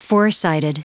Prononciation du mot foresighted en anglais (fichier audio)
Prononciation du mot : foresighted